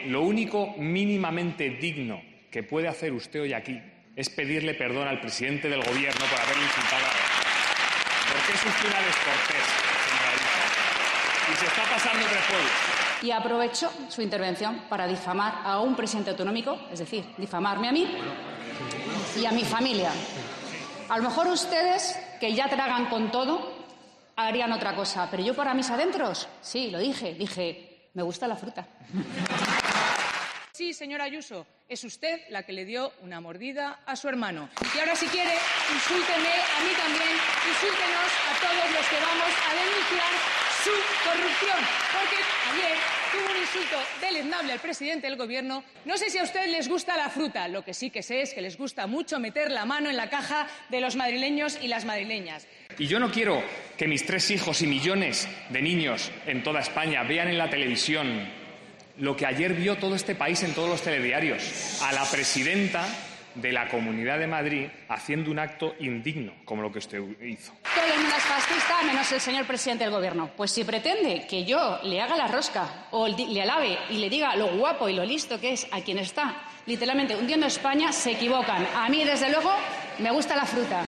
Cruce de declaraciones